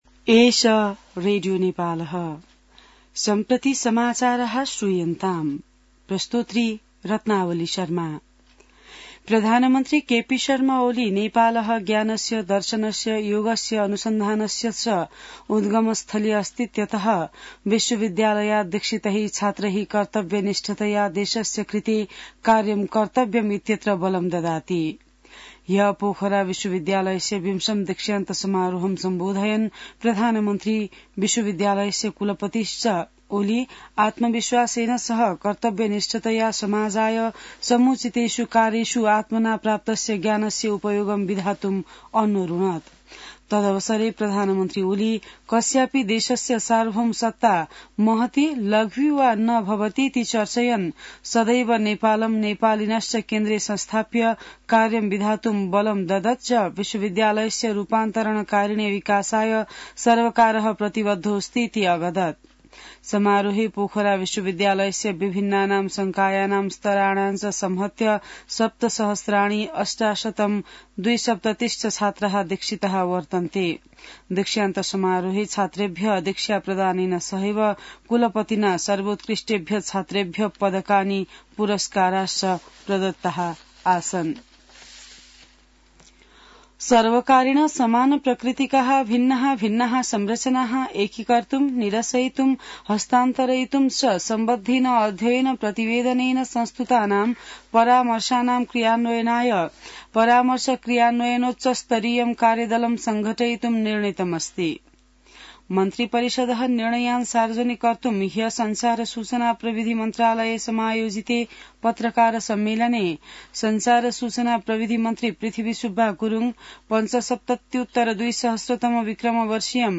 संस्कृत समाचार : १० फागुन , २०८१